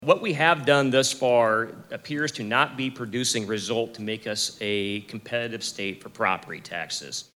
Jochum and Dawson made their comments at the winter meeting of the Iowa Taxpayers Association.